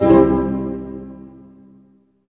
Index of /90_sSampleCDs/Roland LCDP08 Symphony Orchestra/HIT_Dynamic Orch/HIT_Tutti Hits